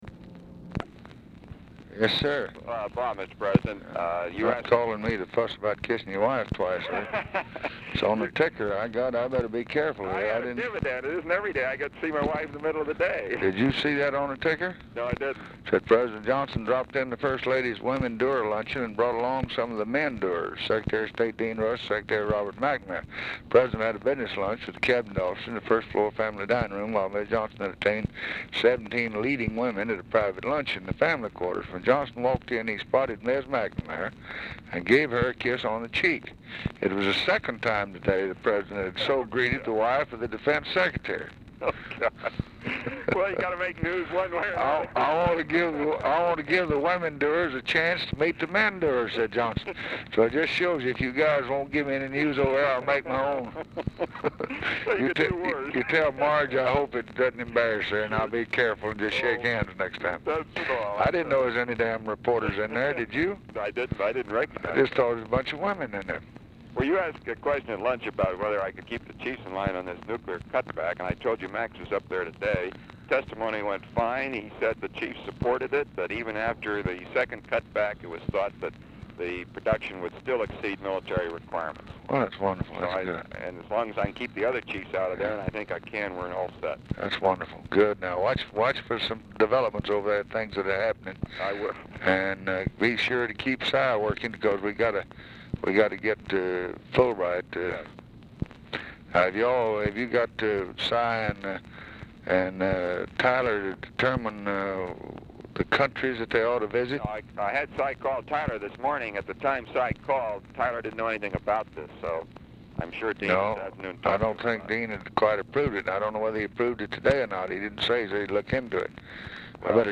Telephone conversation # 3160, sound recording, LBJ and ROBERT MCNAMARA, 4/28/1964, 6:25PM | Discover LBJ
Format Dictation belt
Location Of Speaker 1 Oval Office or unknown location
Specific Item Type Telephone conversation